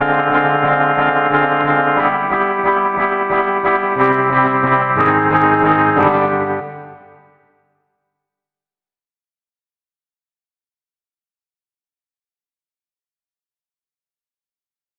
Electric Guitar